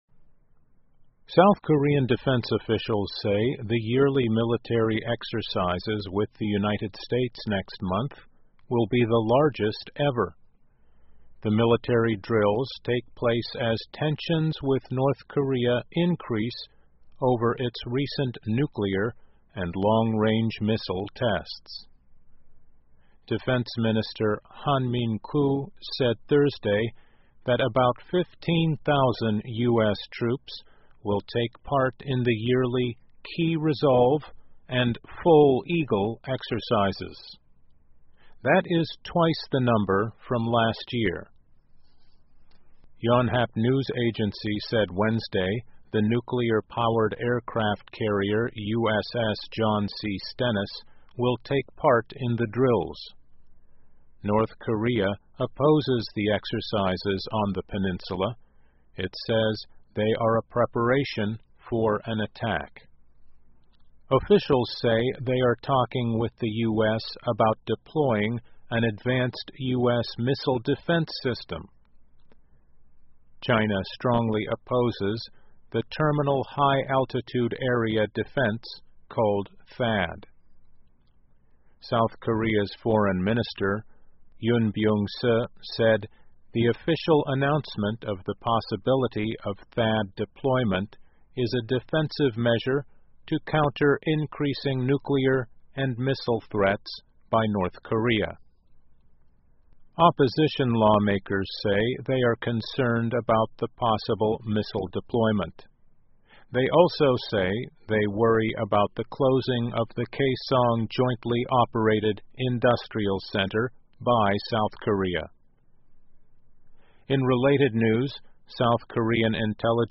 VOA慢速英语--美韩举行史上最大规模的军事演习 听力文件下载—在线英语听力室